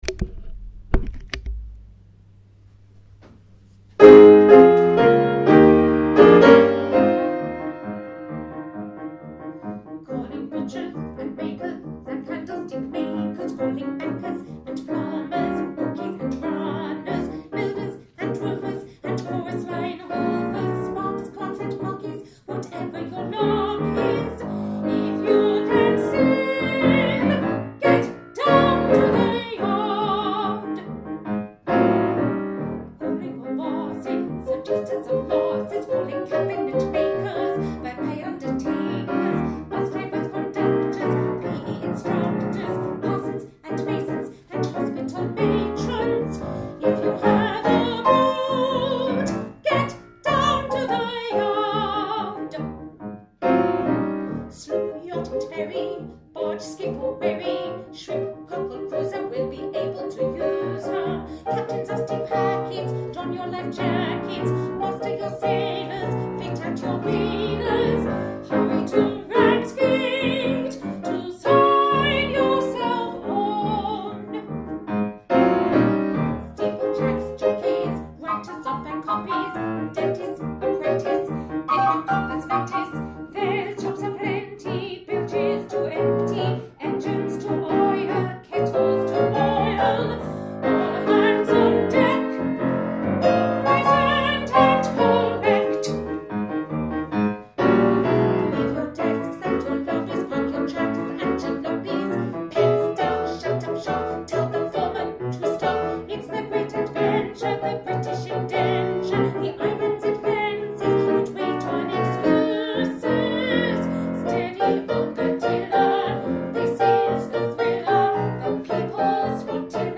Vocal & Piano Audio of Come All Ye